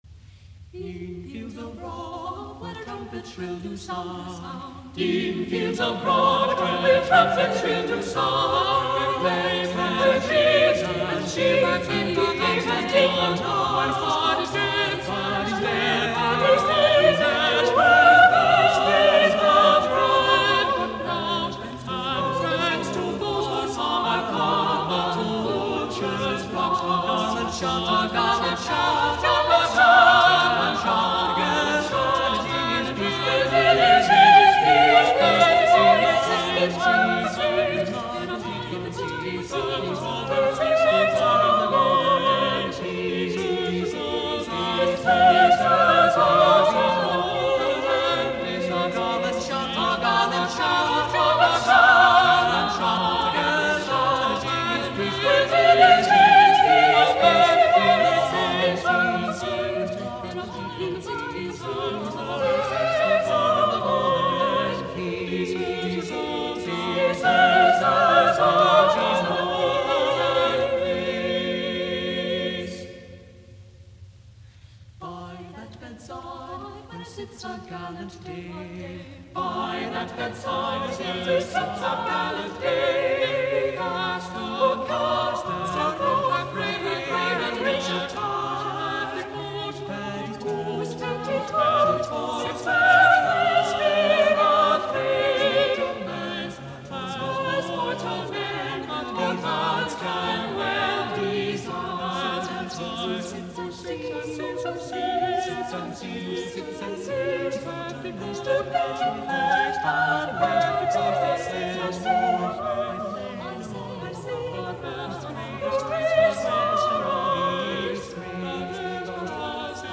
Printed in his ‘Psalmes, Sonets and Songs’ of 1588, this “make love, not war” part-song of Byrd rather flies in the face of the prevailing military stance of the nation in the year of the Armada.
The first and last of four stanzas are sung here.